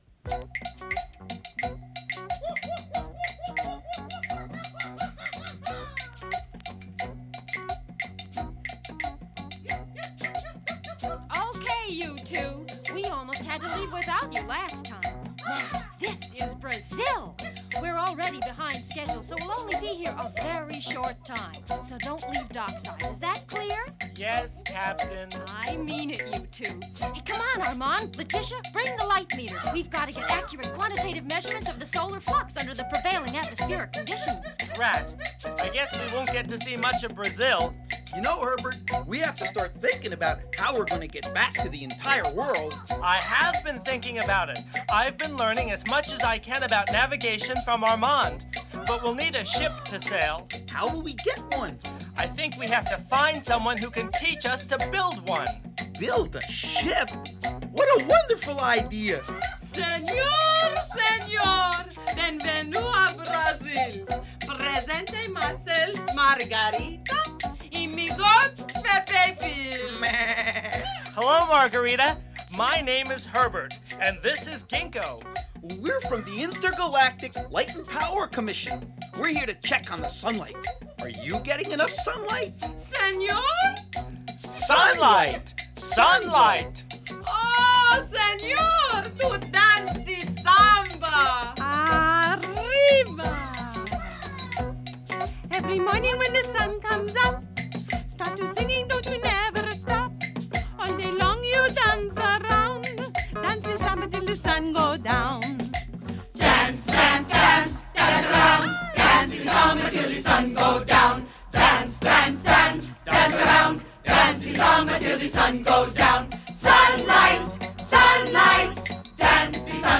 It contains the entire show complete with dialog.